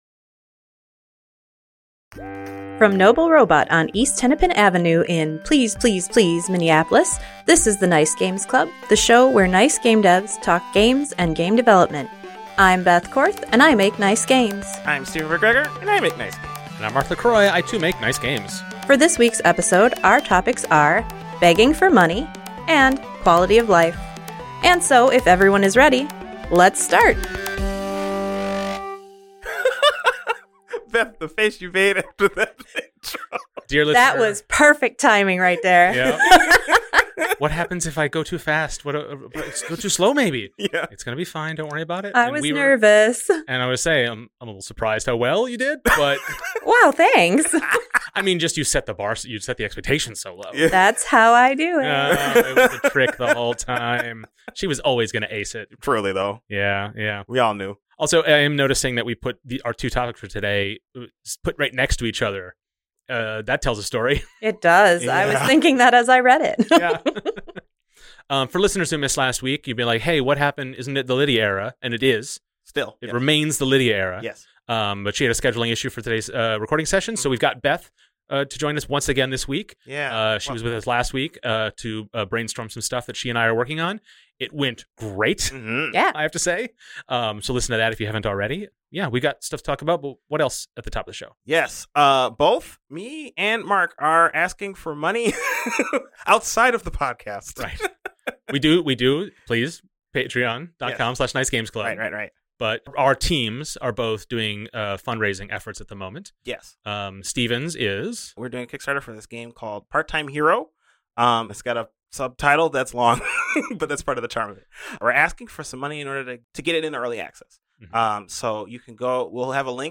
The podcast where nice gamedevs talk gaming and game development.